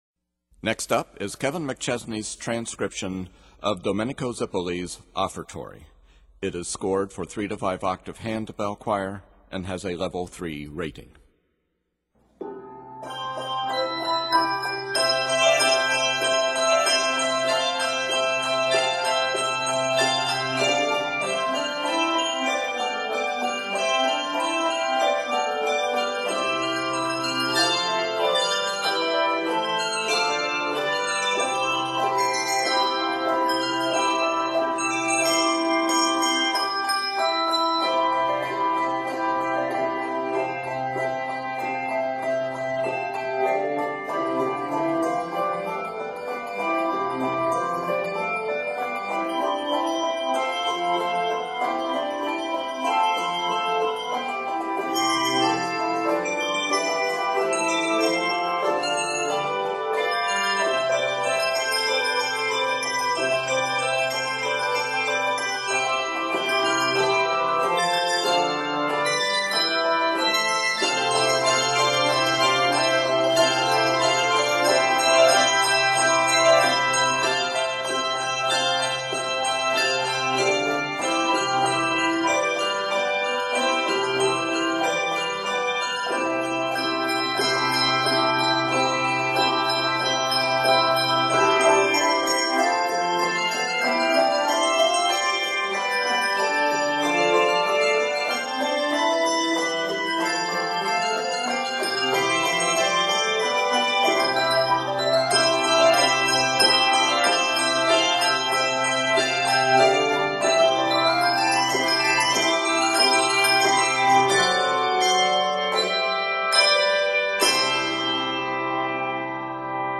Replete with energized, 16th-note rhythms
a study in crisp ringing and good damping
scored in C Major
Octaves: 3-5